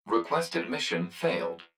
042_Mission_Fail.wav